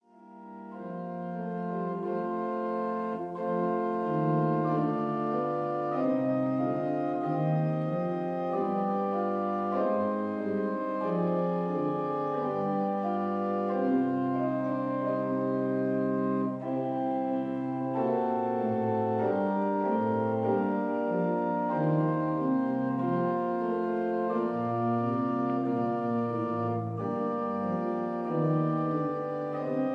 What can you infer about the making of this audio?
Stereo recording made in 1958